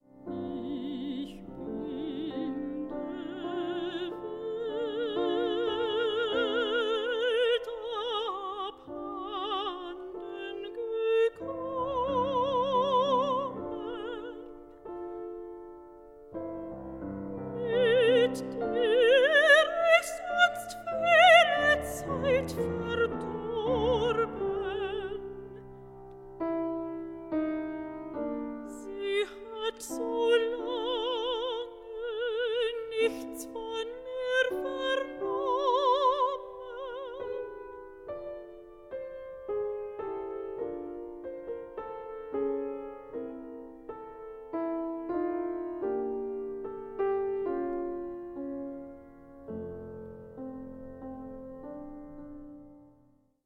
Stereo
mezzo-soprano
piano